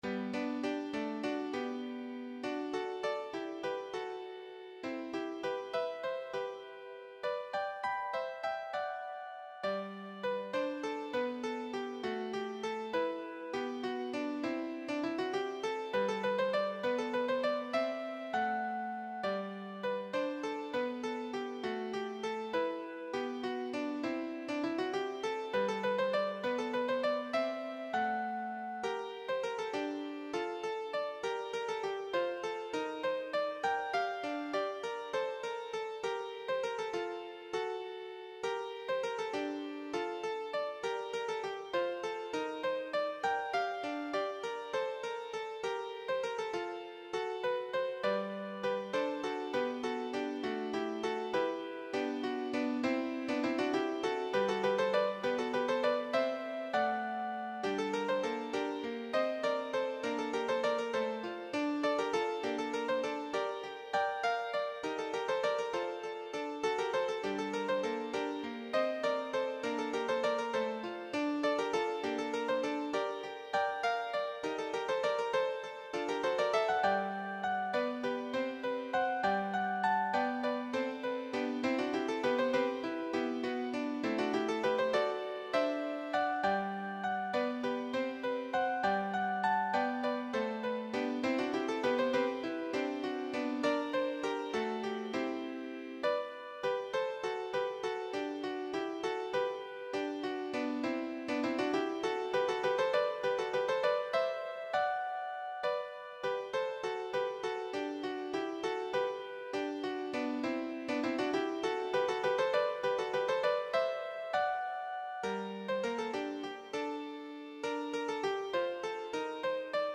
8-er Walzer
Tonumfang G-A2, G-Dur / G- Moll
midi Tonbeispiel Klavier